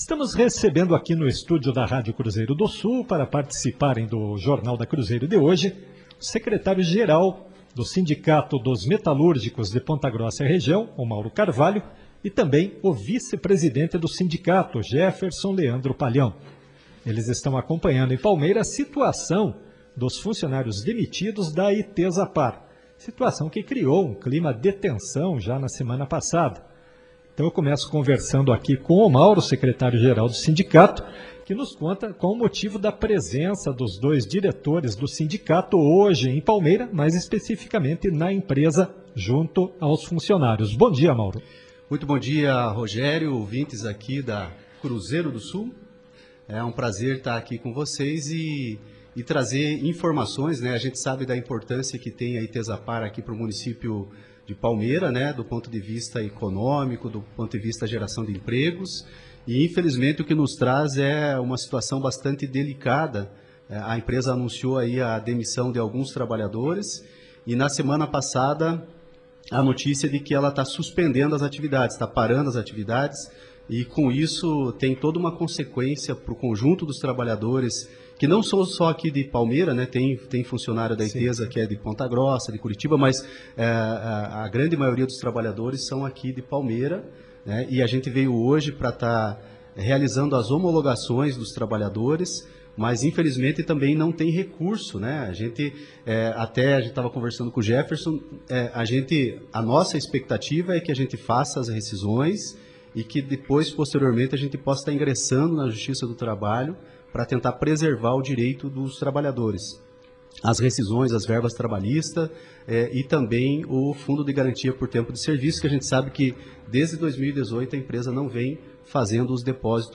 ENTREVISTA-SINDICATO-DOS-METALURGICOS.mp3